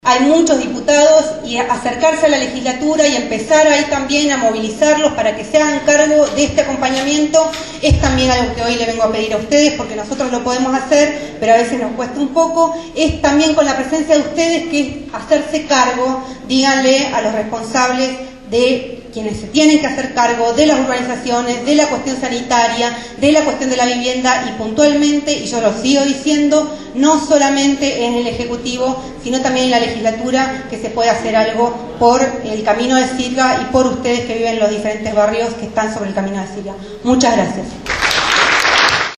El sábado 18 de agosto en la escuela Lafayette de Barracas se realizó un encuentro por el conflicto por la relocalización de las familias que viven en el camino de sirga.
Por su parte Rocío Sanchez Andía, legisladora porteña por la Coalición Cívica, propuso la legislatura como un espacio para convocar a nuevos encuentros.